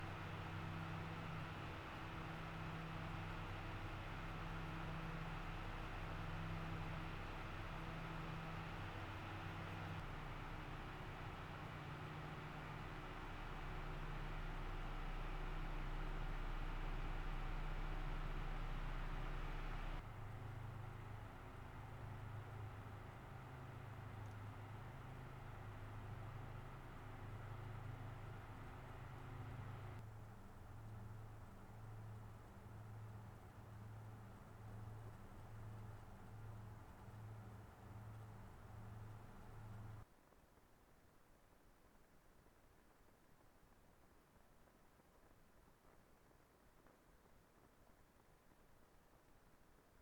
Aufnahme weiterer Kühler (anderes Verfahren, anderes Mikrofon)
Arctic Freezer 36 ARGB: Lautstärke von 100 bis 50 Prozent PWM (31 dB)